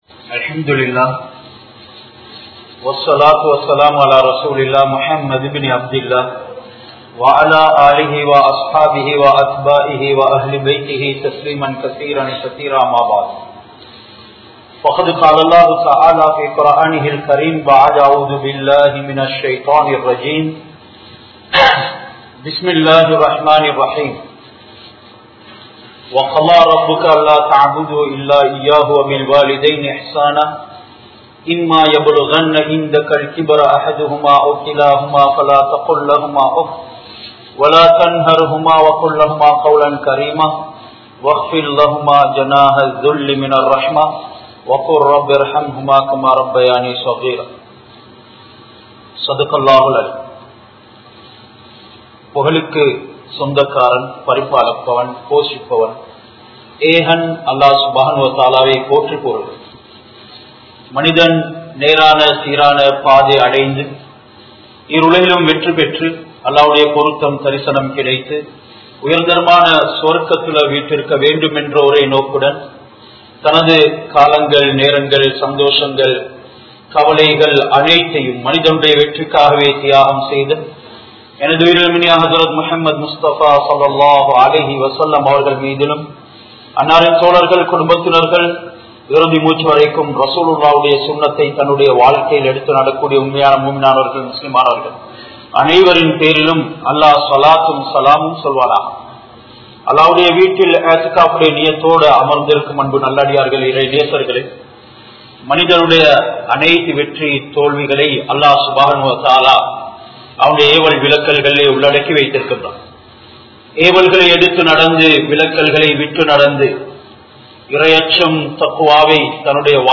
Ungal Petrorukku Munnurimai kodunkal(உங்கள் பெற்றோருக்கு முன்னுரிமை கொடுங்கள்) | Audio Bayans | All Ceylon Muslim Youth Community | Addalaichenai
Wattegama Jumuah Masjith